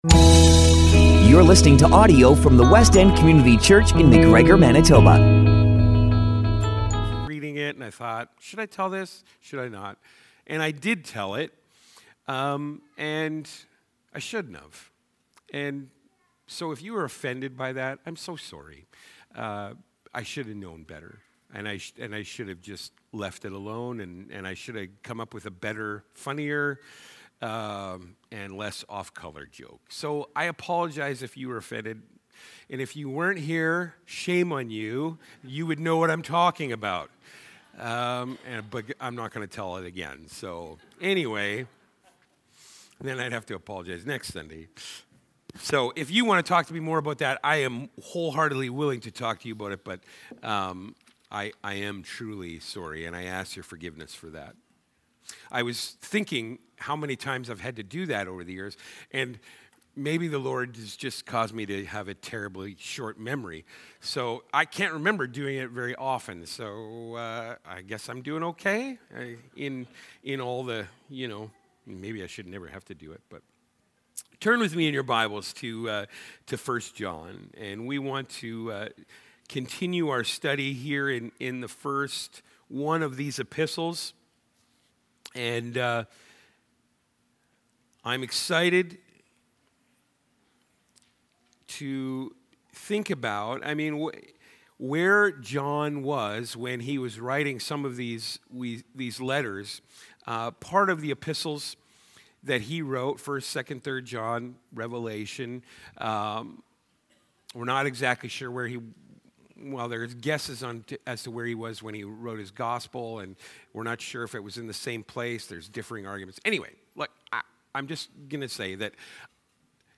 Sermons - Westend Community Church